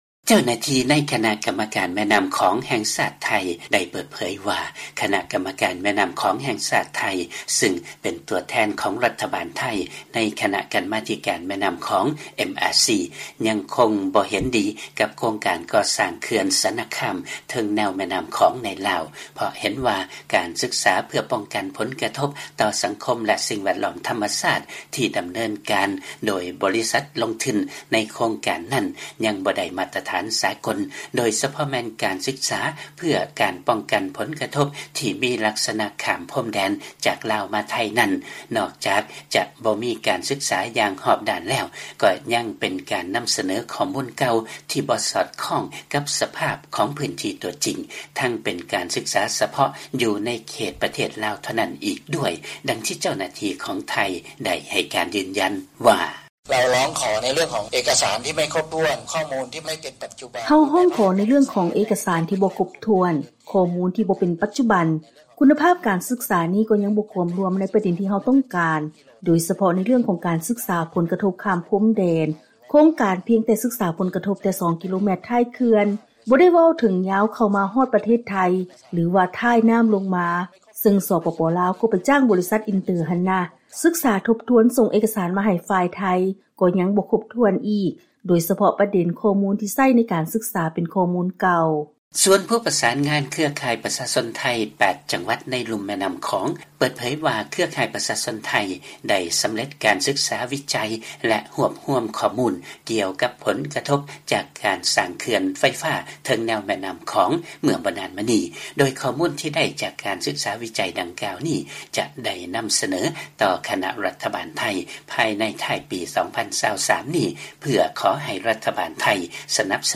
ເຊີນຟັງລາຍການເມືອງລາວໃນປັດຈຸບັນ ກ່ຽວກັບພາກສ່ວນທີ່ກ່ຽວຂ້ອງໃນແມ່ນໍ້າຂອງ ຂອງໄທ ຂັດຄ້ານການສ້າງເຂື່ອນສະນະຄາມໃນ ສປປ ລາວ